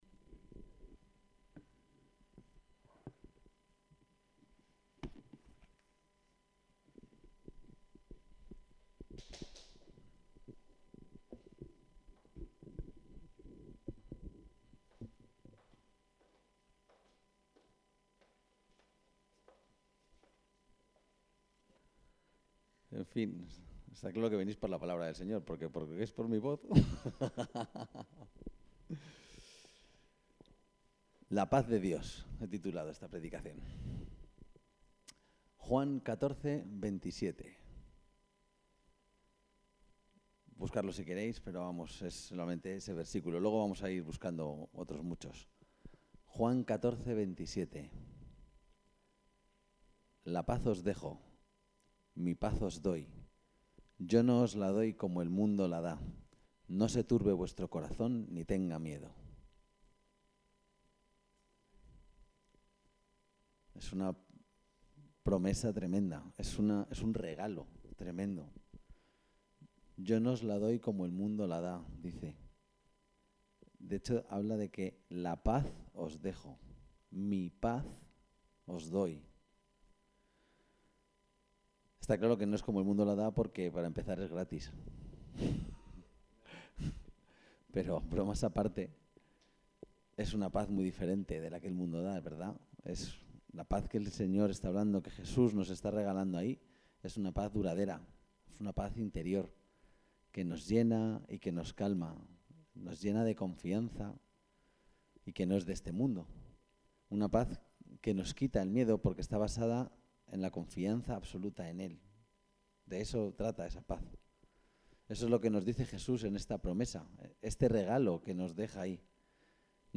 Aquí tenéis el texto de la predicación La paz de Dios